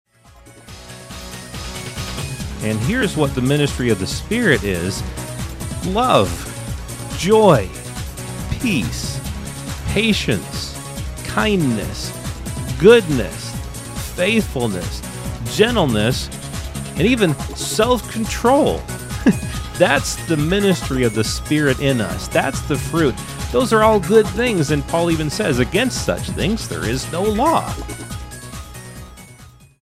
Join us for another good news conversation on this week's Growing in Grace podcast.